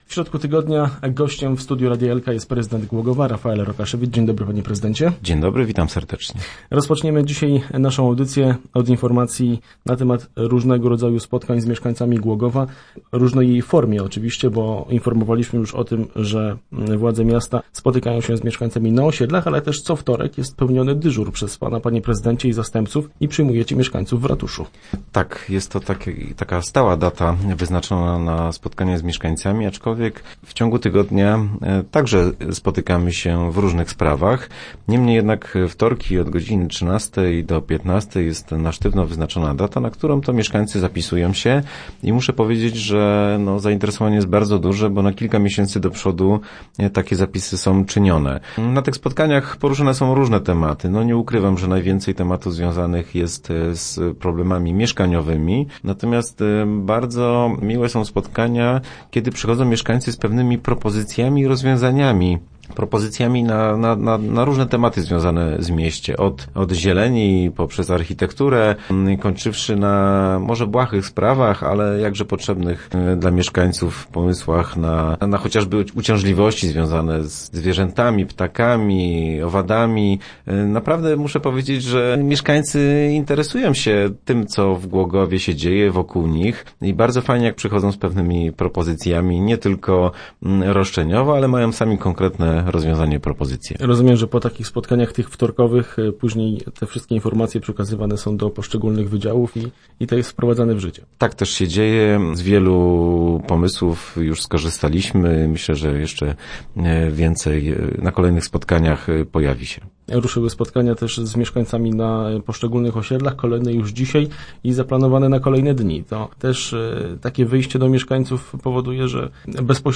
0429_rokasz_re.jpgW środowych Rozmowach Elki o spotkaniach z mieszkańcami, budżecie obywatelskim oraz zbliżającej się majówce rozmawialiśmy w radiowym studiu z prezydentem Głogowa Rafaelem Rokaszewiczem.